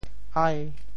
“嫒”字用潮州话怎么说？
潮州 ain3 白 ai5 白
ai5.mp3